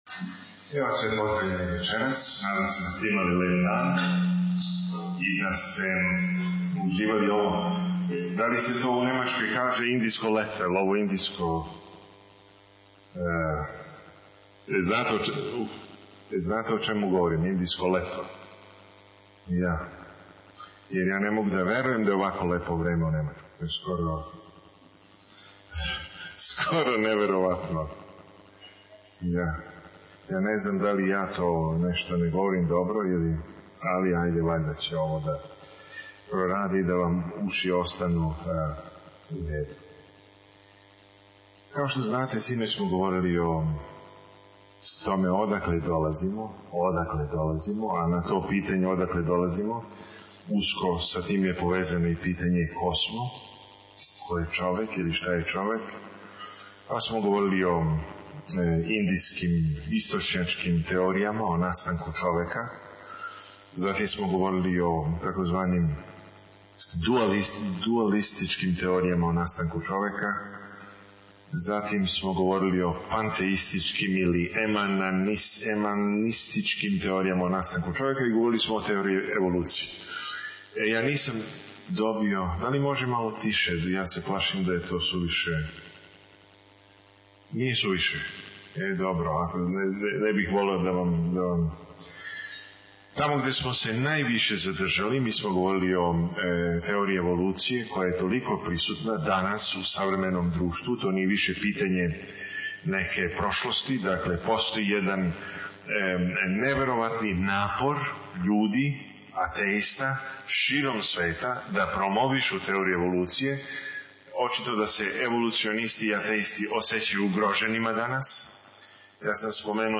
on 2010-01-31 - Predavanja 1 - 8 mp3